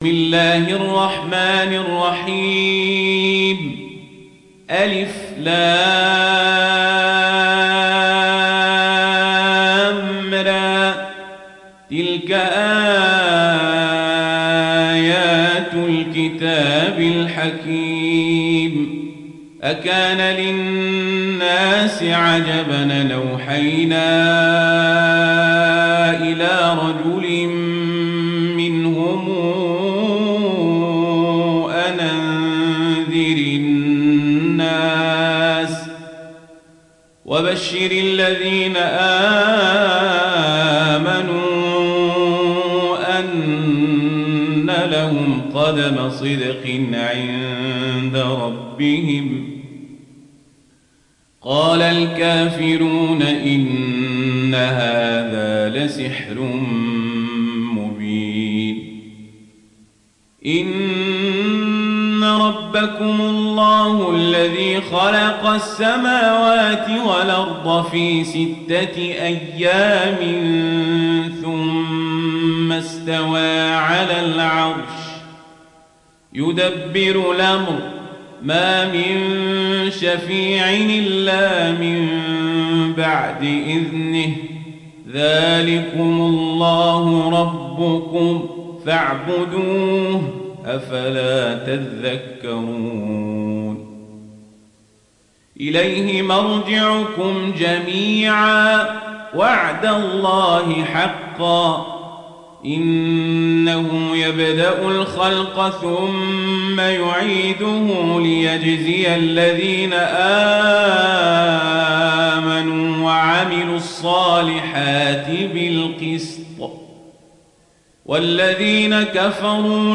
دانلود سوره يونس mp3 عمر القزابري روایت ورش از نافع, قرآن را دانلود کنید و گوش کن mp3 ، لینک مستقیم کامل